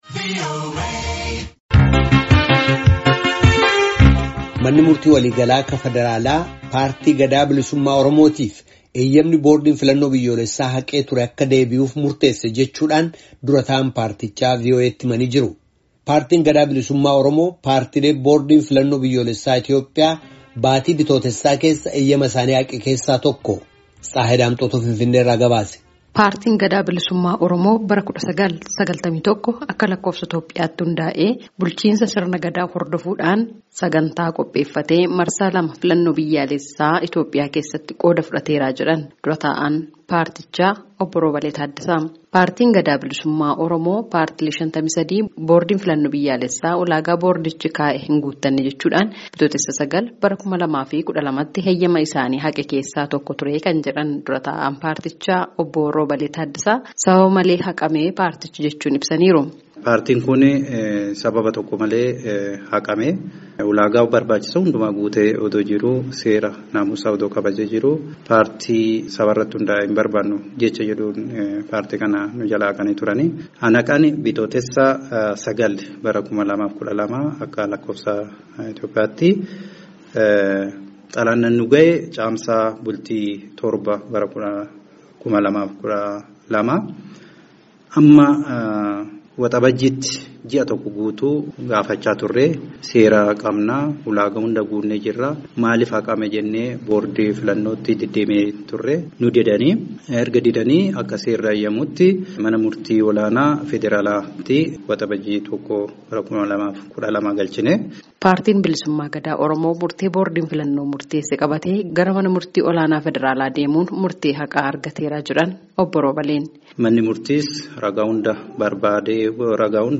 Finfinnee irraa gabaase.